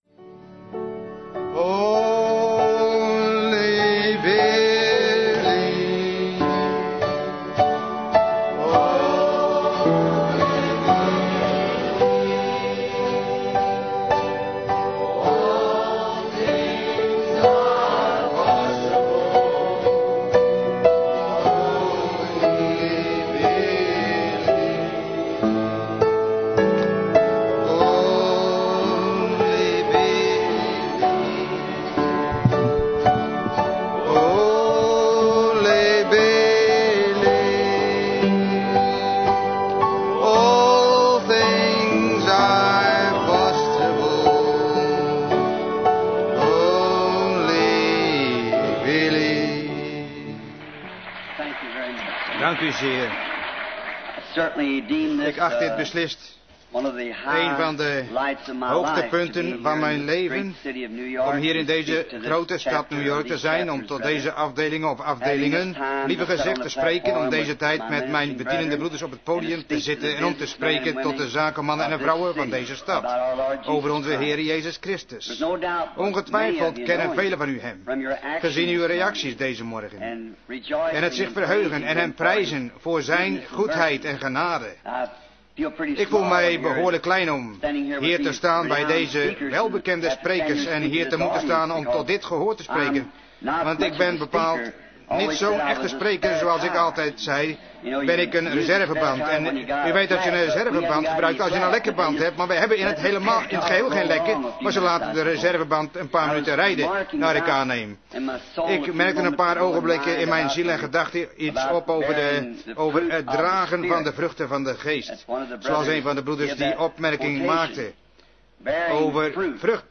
Vertaalde prediking "Investments" door William Marrion Branham te Statler hotel, New York, New York, USA, 's ochtends op zaterdag 16 november 1963